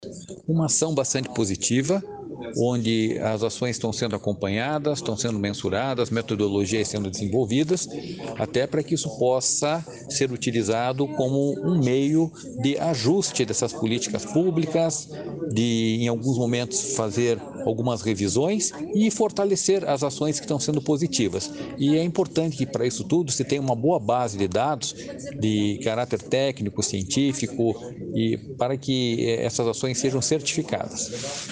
Sonora do diretor-presidente do Ipardes, Jorge Callado, sobre o Plano Plurianual estadual